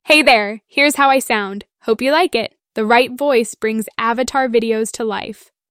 Breezy Beatrice - Excited 🤩
🌍 Multilingual👩 Женский
Пол: female
Этот скрипт отображает тайские голоса и голоса с поддержкой множественных языков из HeyGen API с возможностью фильтрации.